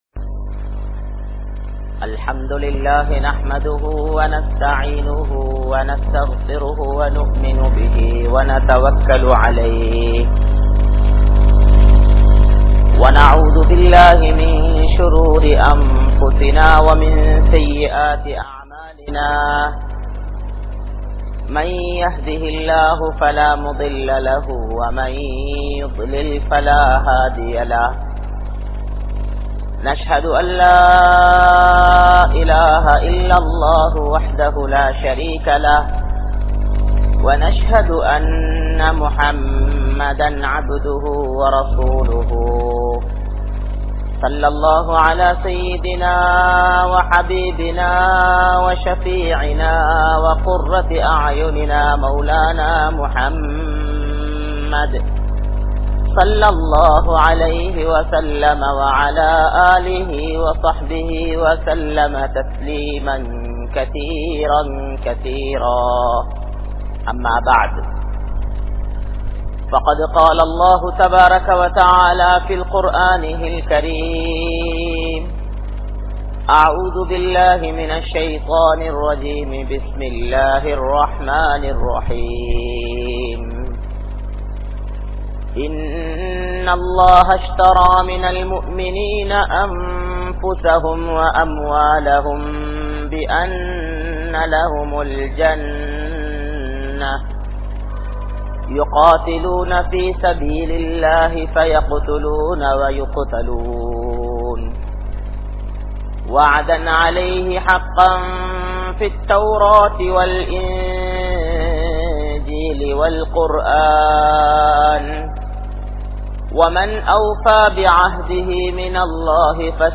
Dhunyaa Thaarihal (துன்யாதாரிகள்) | Audio Bayans | All Ceylon Muslim Youth Community | Addalaichenai